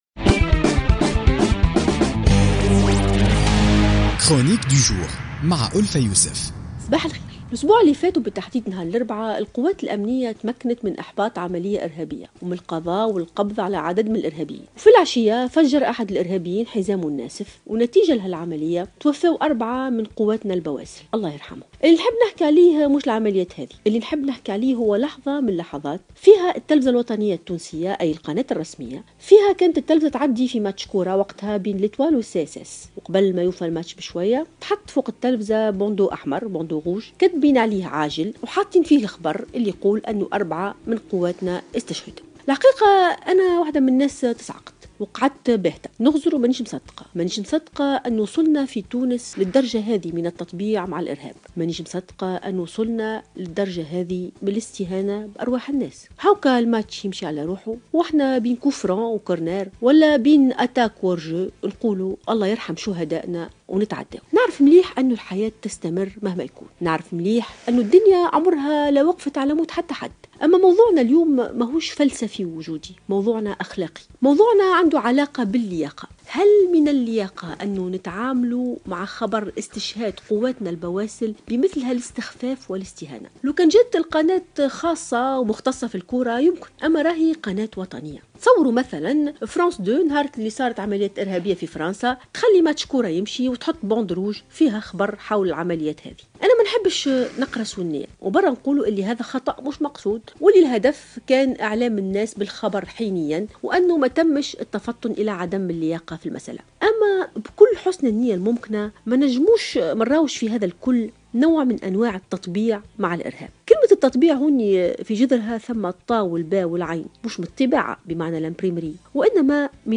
تحدثت الباحثة ألفة يوسف في افتتاحية اليوم الاثنين 16 ماي 2016 عن مسألة "التطبيع" مع الإرهاب من خلال طريقة التعامل مع الأحداث الارهابية.